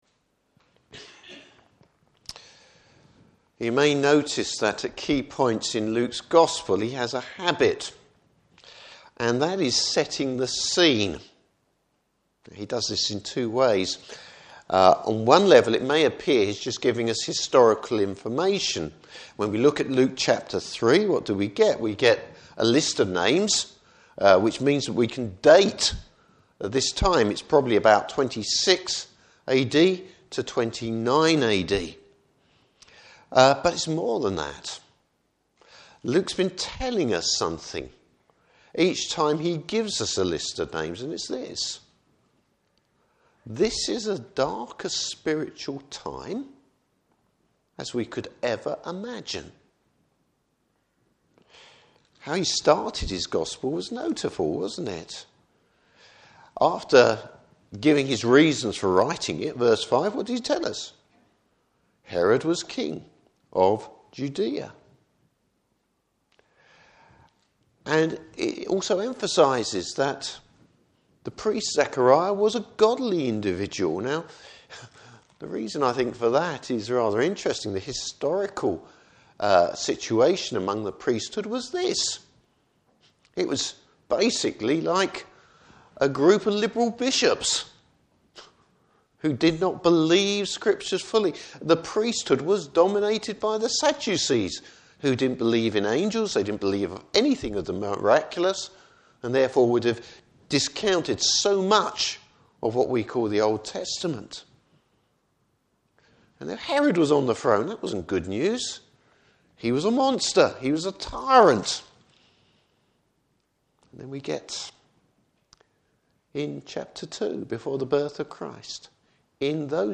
Service Type: Morning Service Bible Text: Luke 3:1-20.